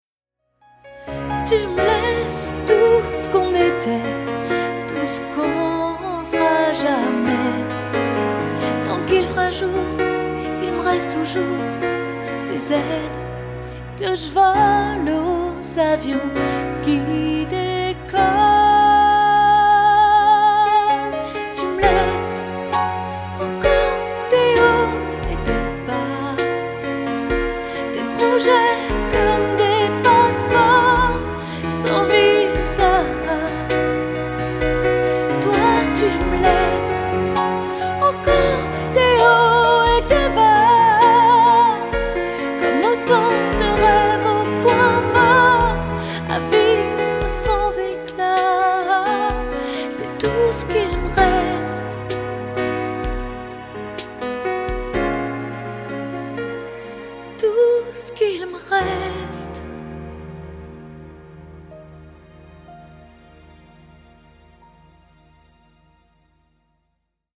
Pop Rock
chanson française
Chanteuse